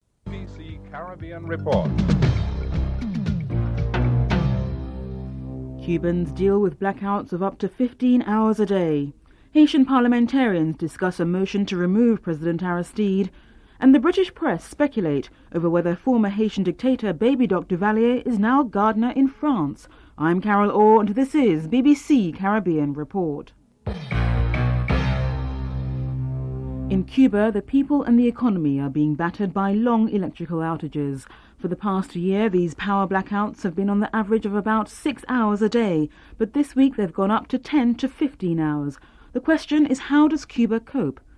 9. Wrap up & theme music (14:40-14:57)